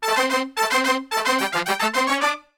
FUNK1 DM.wav